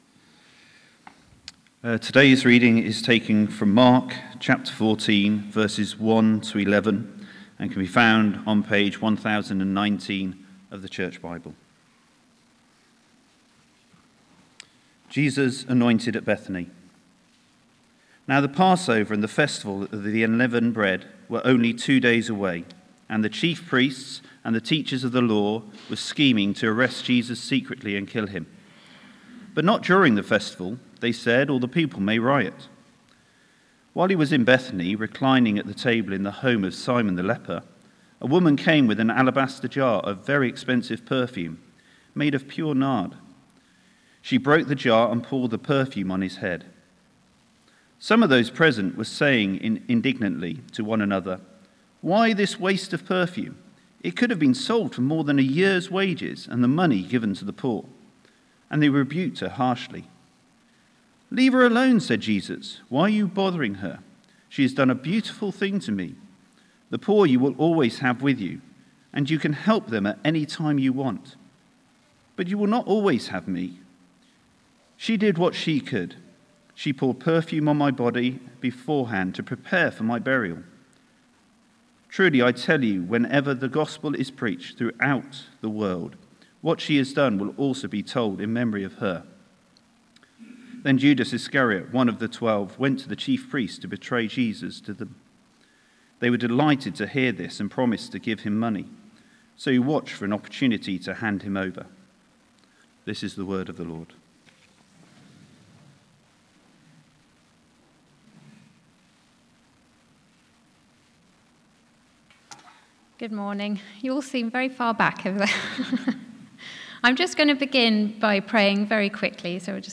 Service Type: Sunday 11:00am